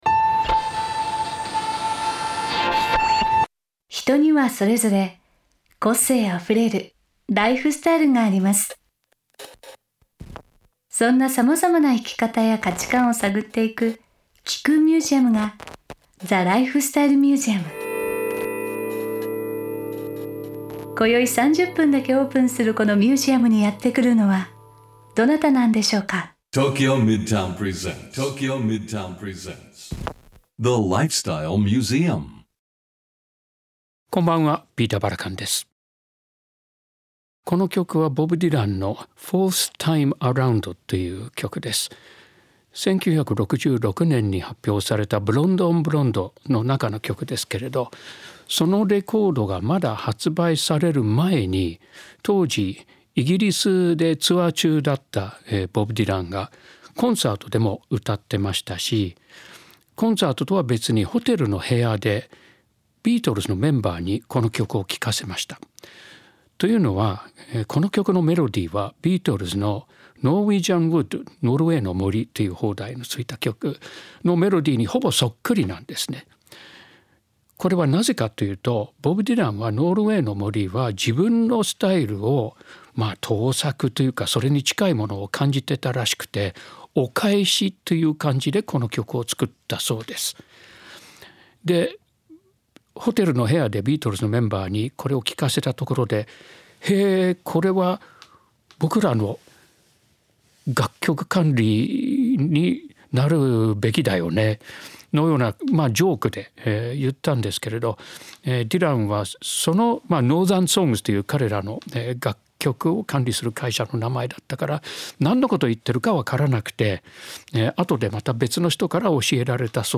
4月17日OAのゲストは、 昨年歌手活動60周年を迎えた加藤登紀子さんです。